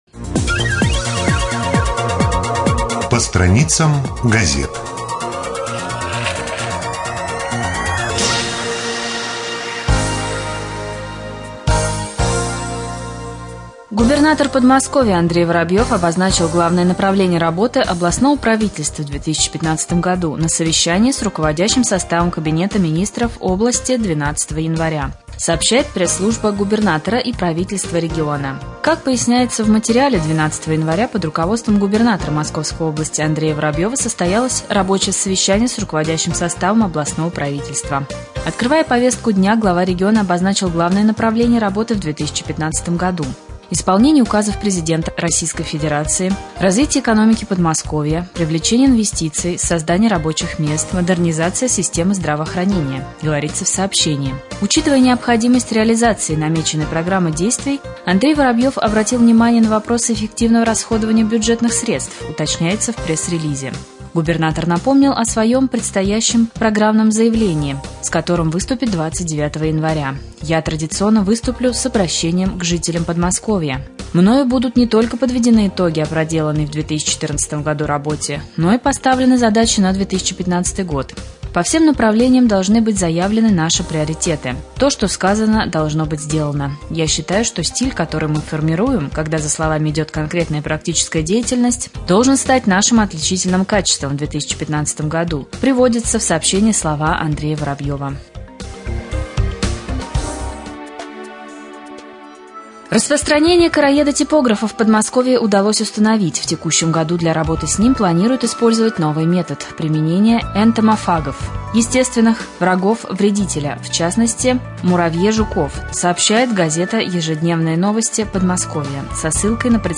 1.Новости-Подмосковья.mp3